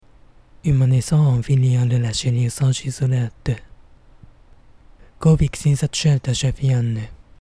↓rlt(上方lt)とarkaの音声比較